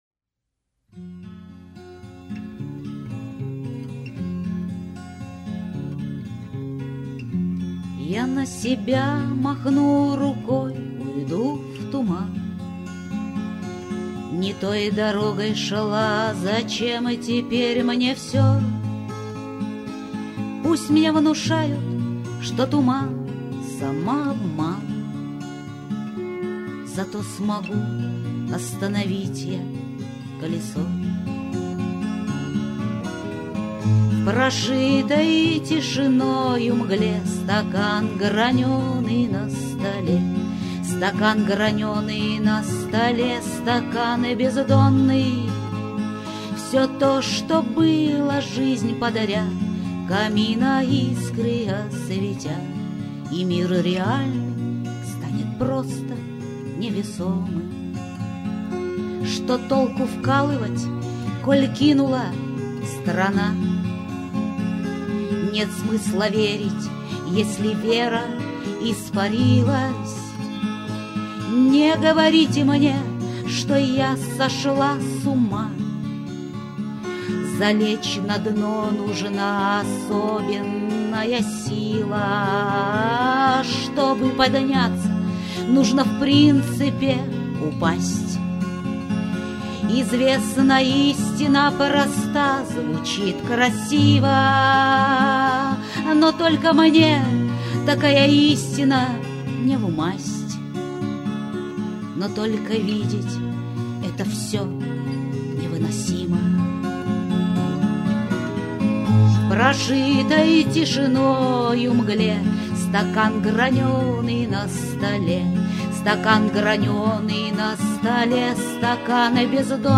Многие записи, здесь представленные, неважного качества, записывалось всё, когда я только начинала осваивать компьютер) Со своих страничек в инете я их убрала давно, и очень приятно, что Вы разыскали эти старые песни)